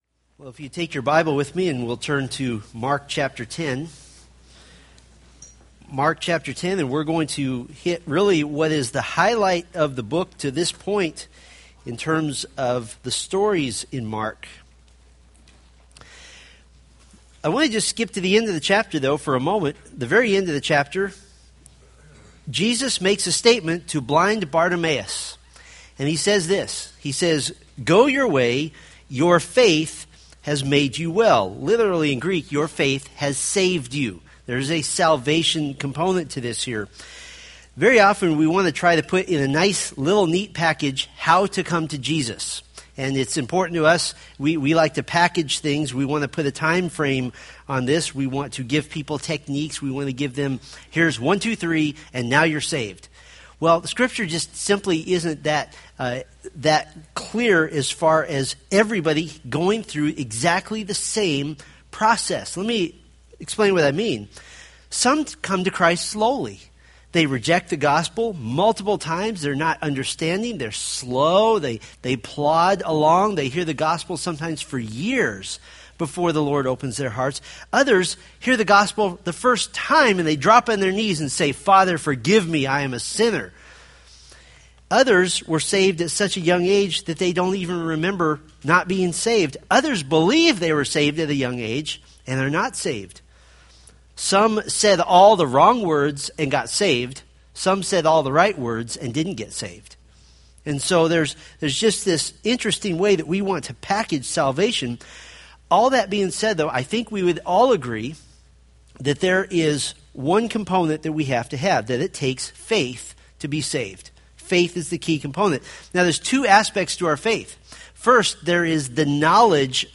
Homepage of Steadfast in the Faith, anchoring the soul in the Word of God by providing verse-by-verse exposition of the Bible for practical daily living.